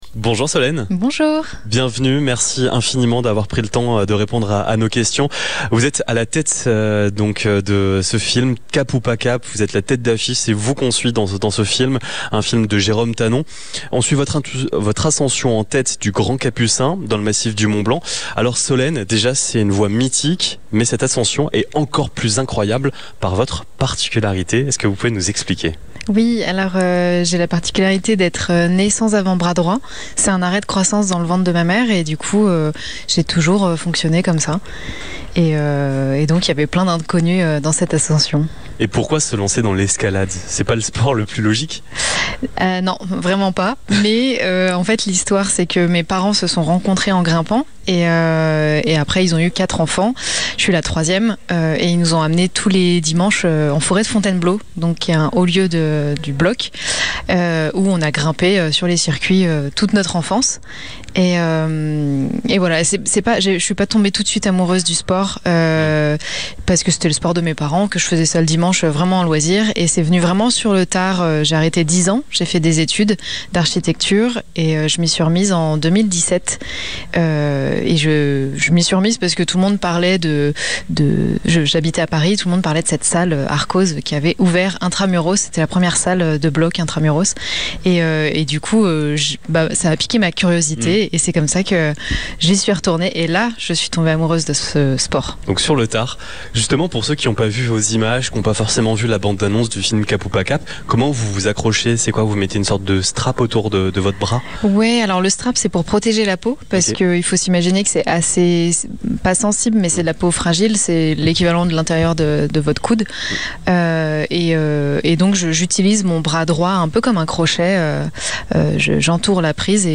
Ce mercredi 26 novembre, nous avons posé nos micros au Ciné Mont-Blanc, le multiplex emblématique de la vallée, à l'occasion du Festival Montagne en Scène.
Interview